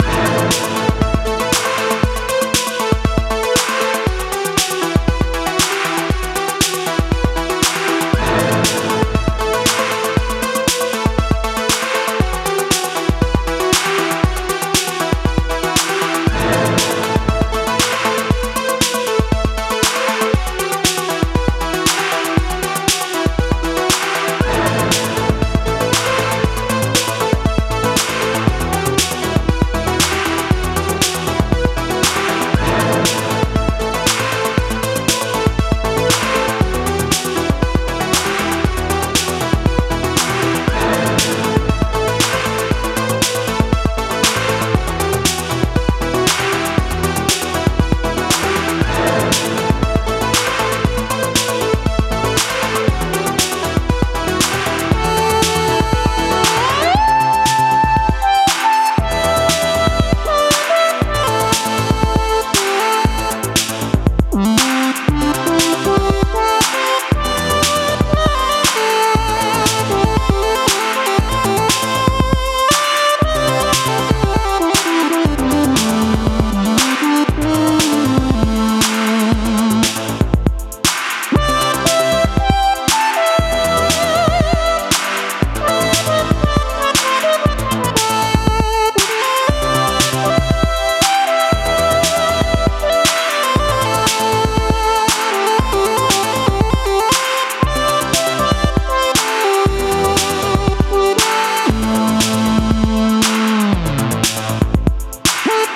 2010年にリリースされていたとは俄には信じ難い、まんま1984年か1985年の音。